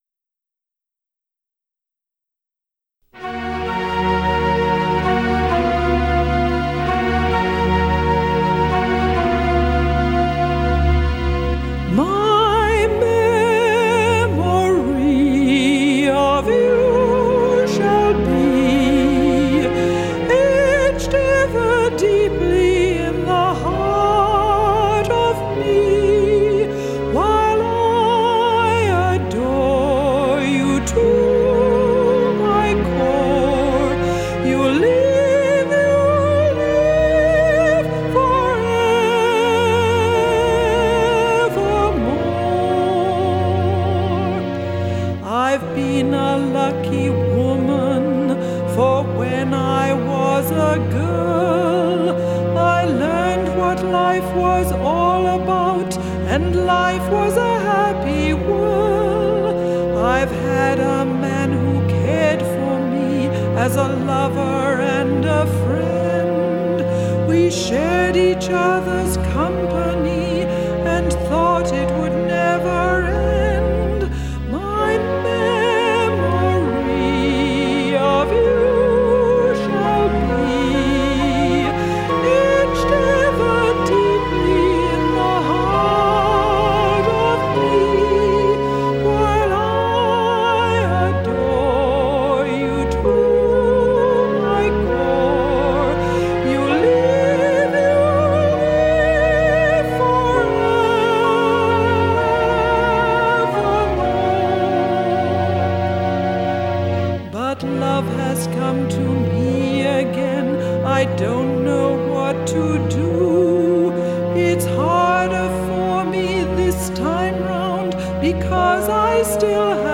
Distress Call     - a Light Opera
Auntie Kath (alto)